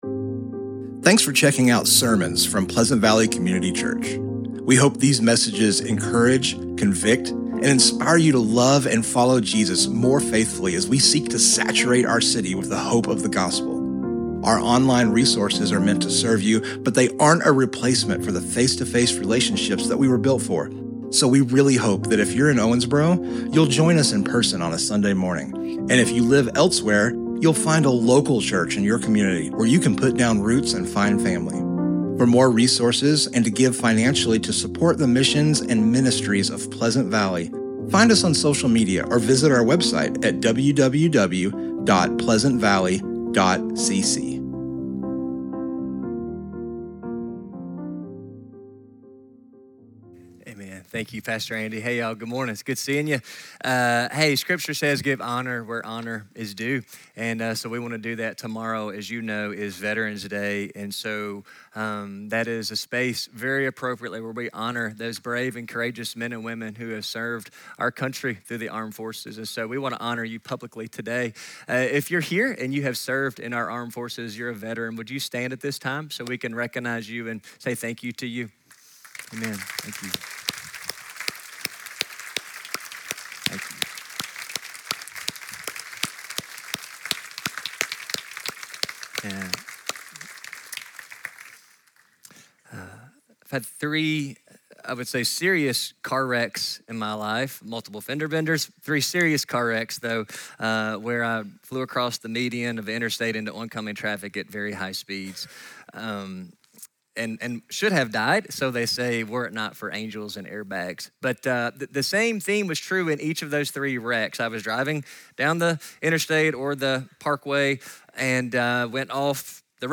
Sermons Archive